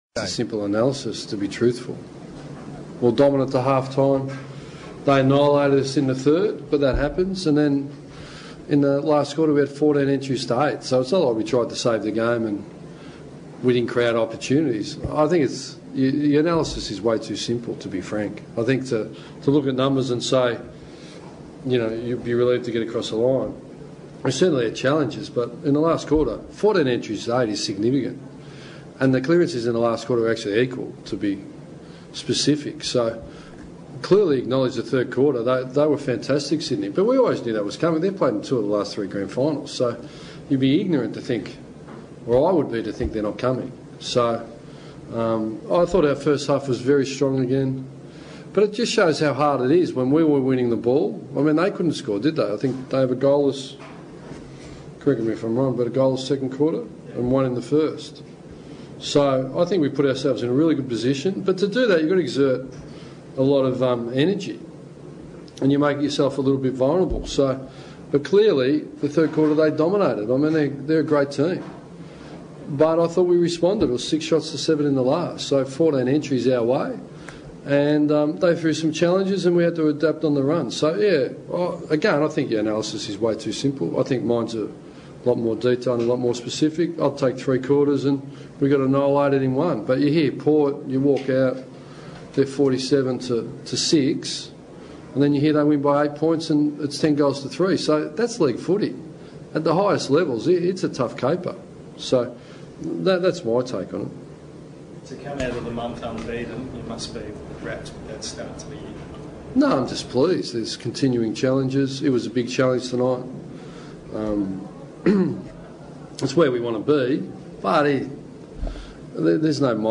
Ross Lyon's post match press conference.